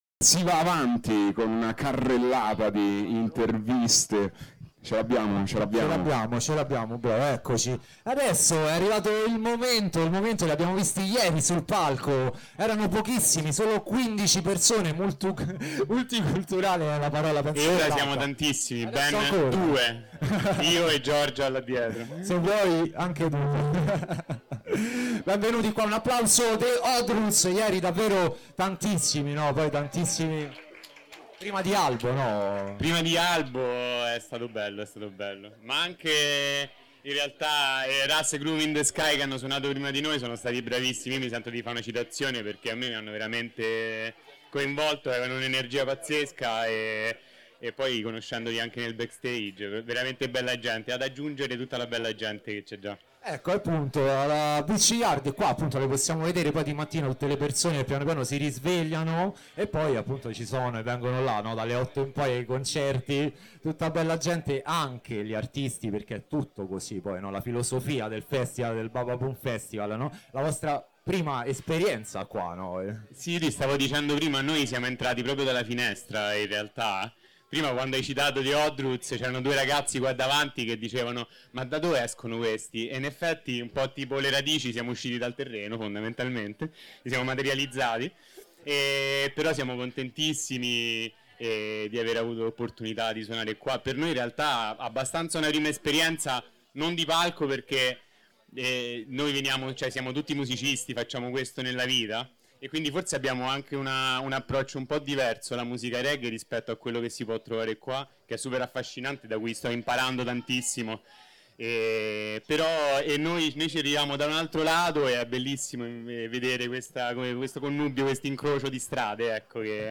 🎙 The Oddroots – 15 Cuori, Mille Suoni 📍 Intervista esclusiva su Radio Bababoom · Bababoom Festival 2025 · Marina Palmense 🎧 In onda su Radio Città Aperta · Media partner ufficiale
🌍 Dalla spiaggia del Bababoom Festival, l’intervista si trasforma in un viaggio musicale e umano: